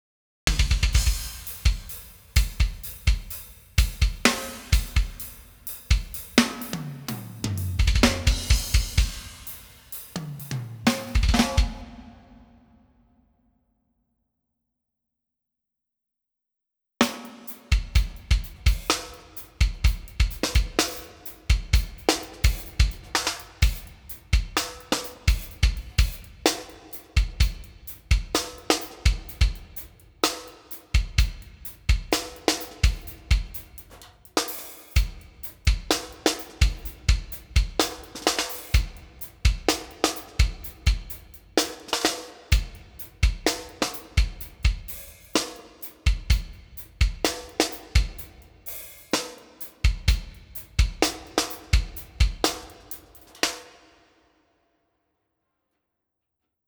For this comparison I mixed a quick ITB drum mix.